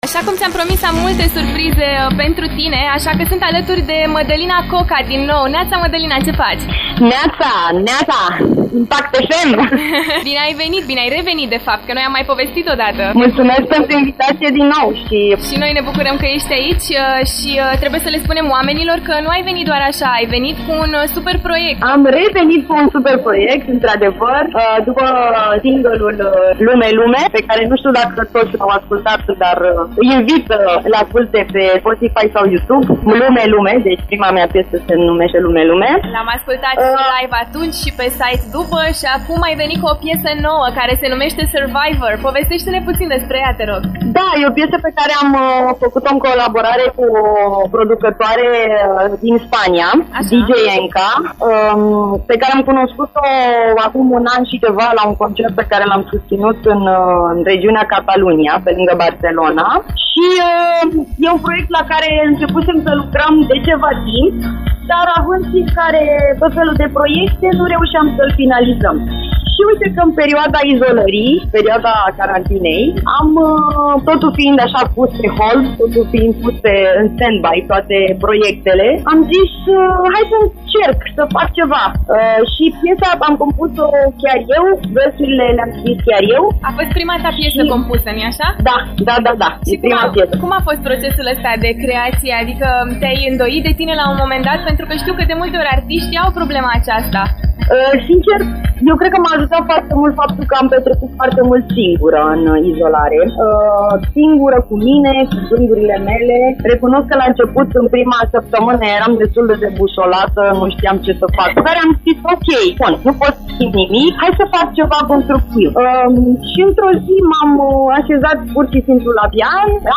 LIVE la After Morning